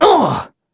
Kibera-Vox_Damage_01.wav